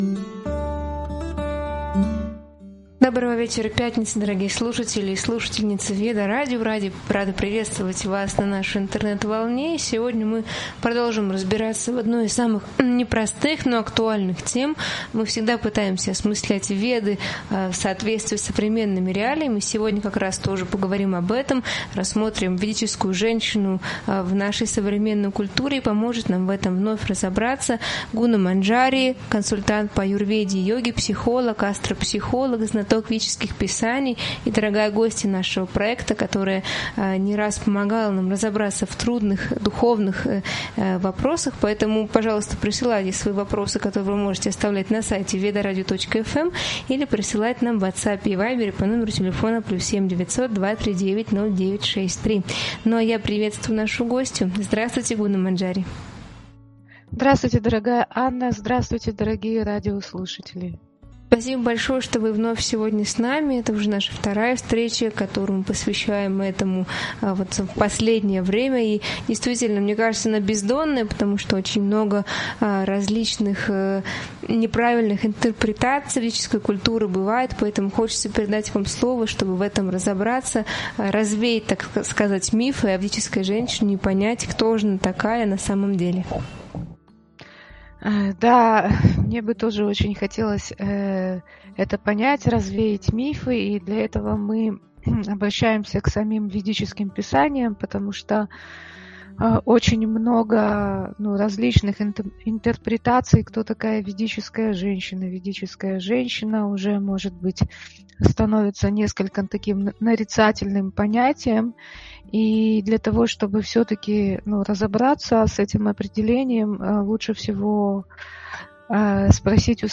В этом эфире обсуждается, как строить гармоничные отношения в семье через понимание личности партнёра, принятие различий и уважение границ. Особое внимание уделяется роли женщины в поддержке и вдохновении мужчины, эмоциональной честности и внутренней самодостаточности.